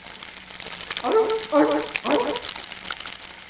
sealion.wav